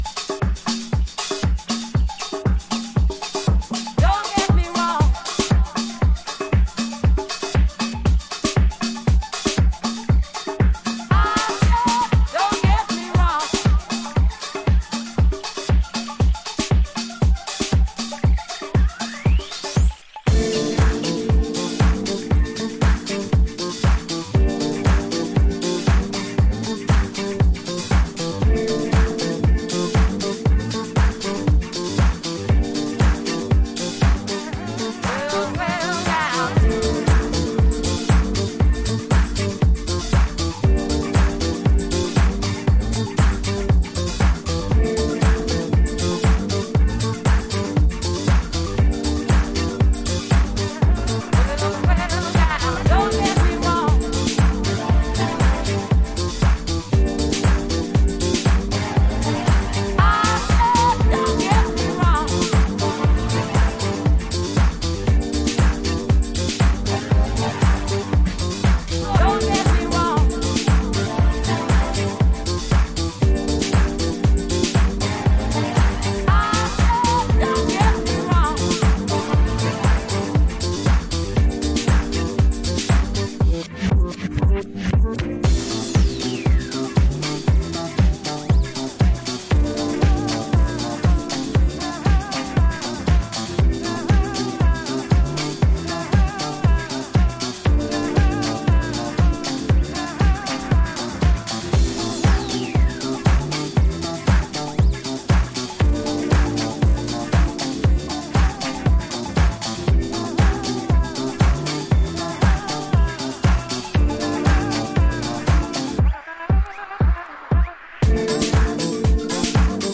煌びやかなパーティー・チューンをメイク。
Alt Disco / Boogie